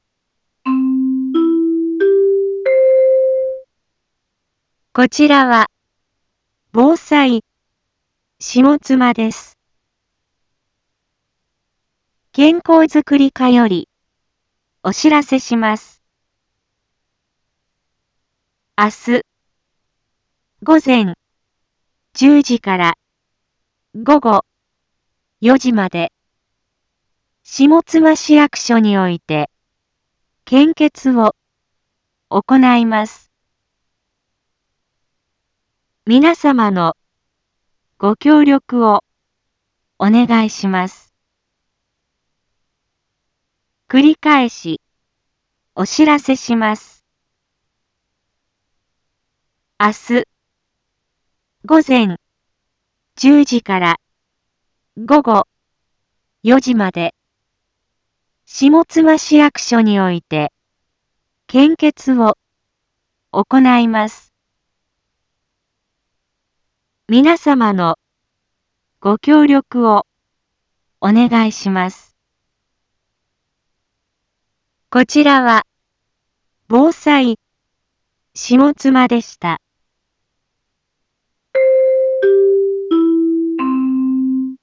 一般放送情報
Back Home 一般放送情報 音声放送 再生 一般放送情報 登録日時：2024-12-18 18:31:46 タイトル：献血のお知らせ（前日） インフォメーション：こちらは、ぼうさいしもつまです。